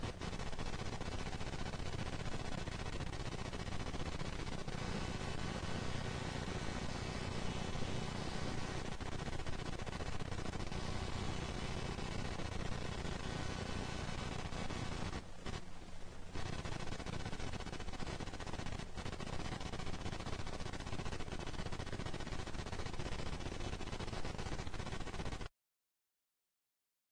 dog
dog.rm